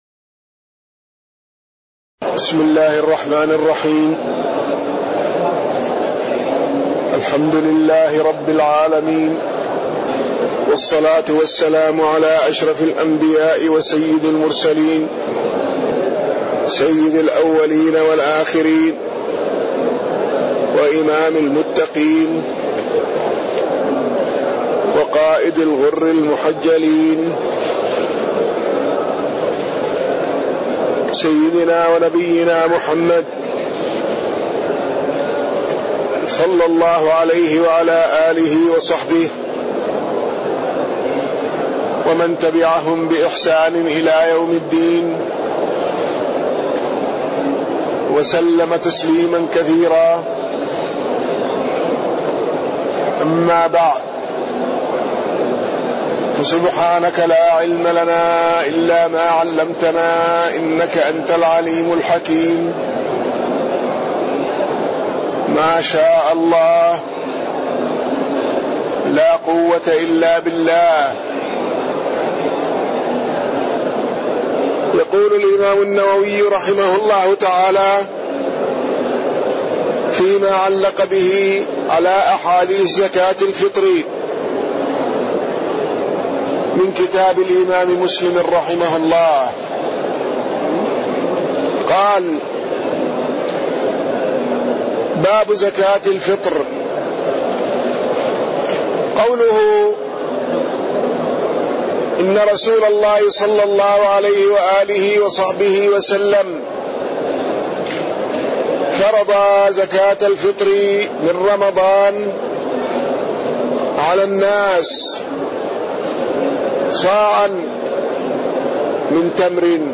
تاريخ النشر ١٣ جمادى الأولى ١٤٣٢ هـ المكان: المسجد النبوي الشيخ